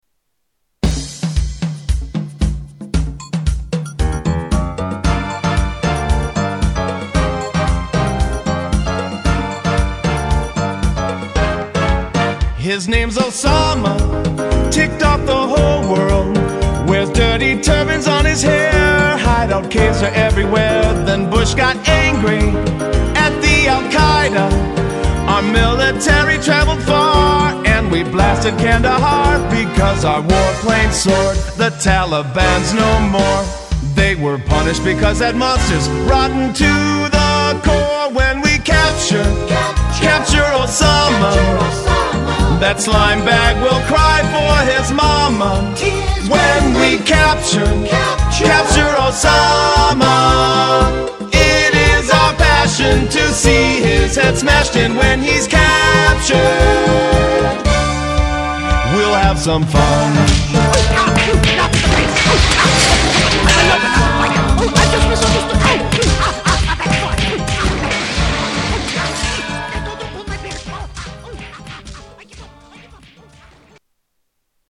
Tags: Media More Parodies Clips Parodies Songs Comedy Spoofs